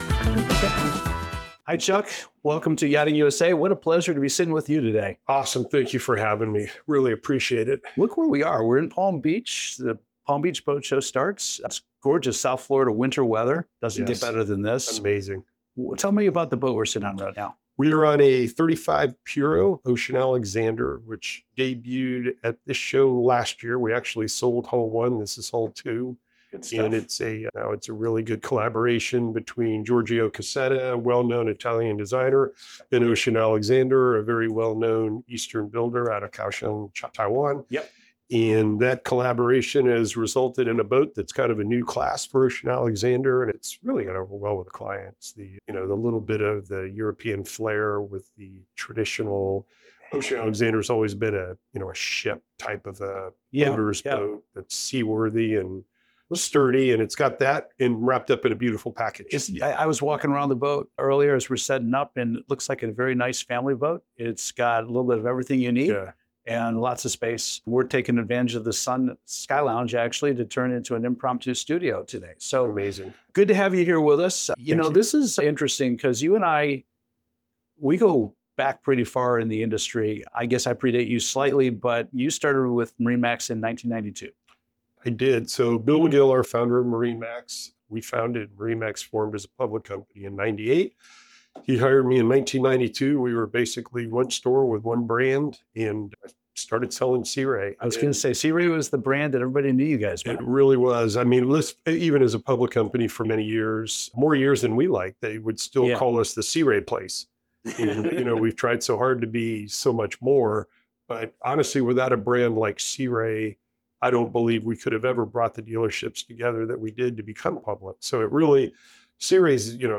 for an in-depth conversation filmed at the scenic Palm Beach Boat Show.